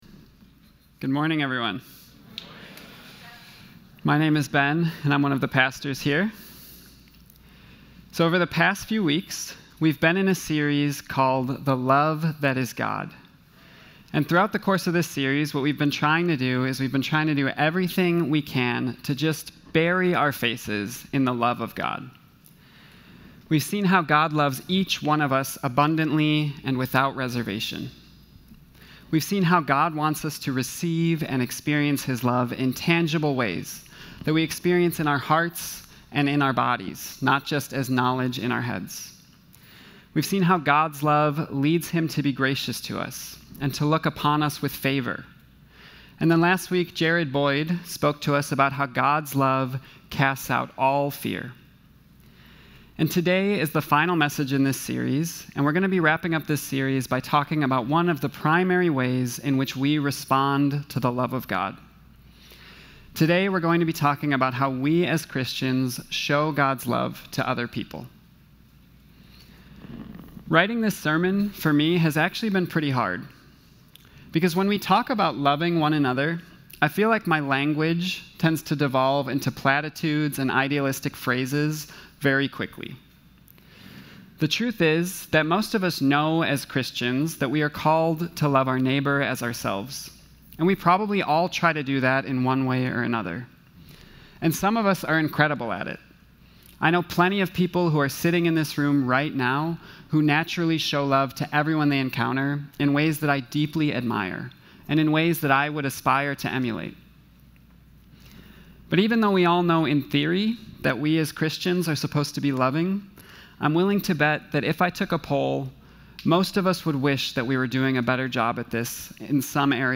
brings the last message in our series on the love of God.